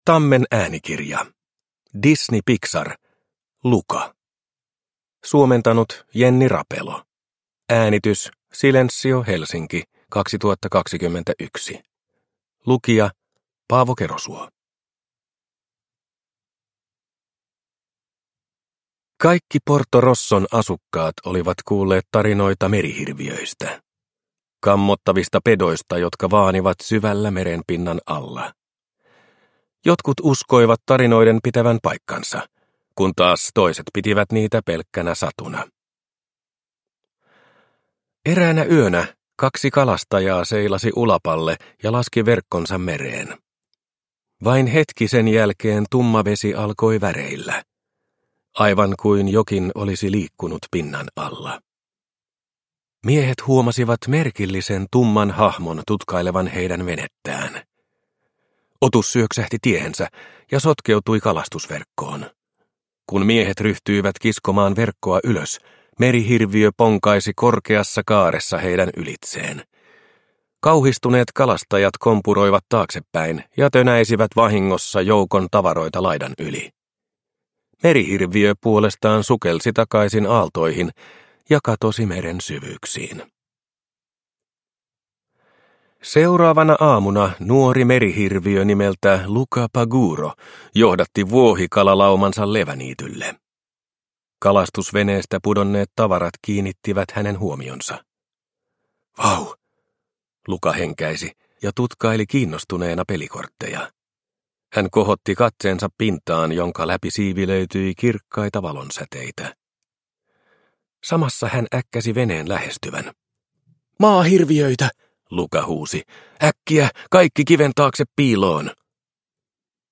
Disney. Luca. Satuklassikot – Ljudbok – Laddas ner